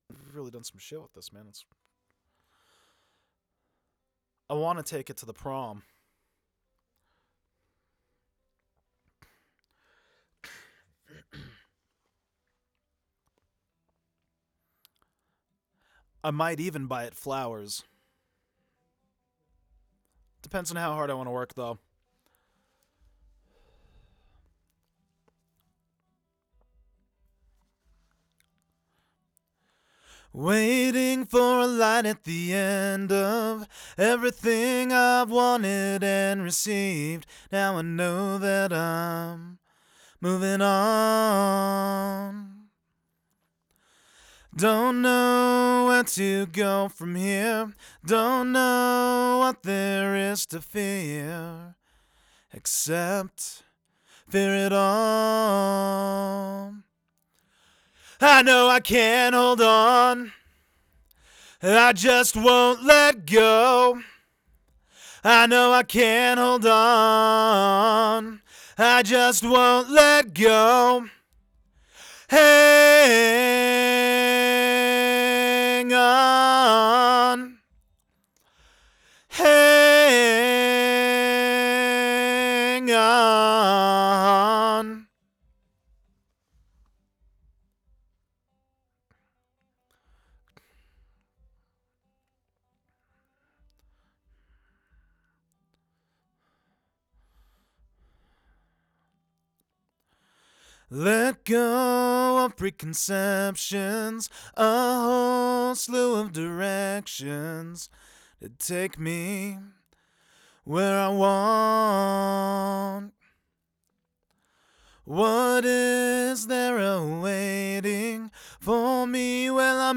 Booth Vocals_028.wav